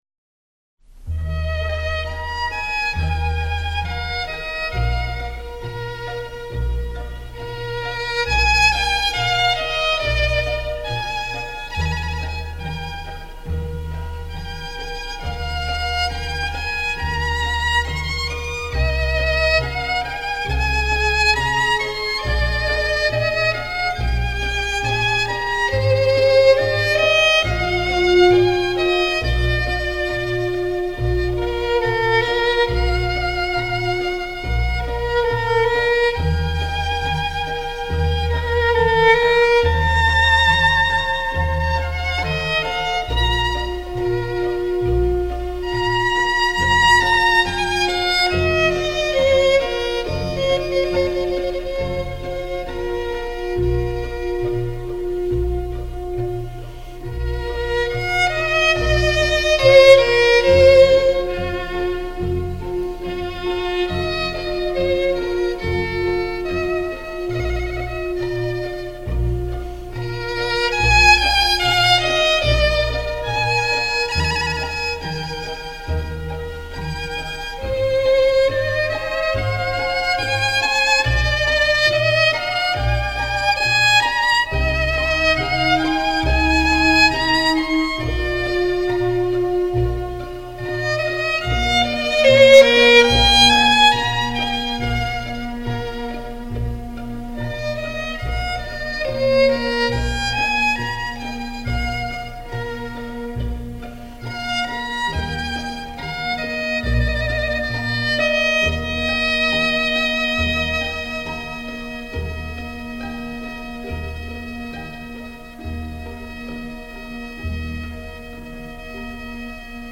Концерты для скрипки с оркестром `Времена года`
Камерный оркестр Ленинградской филармонии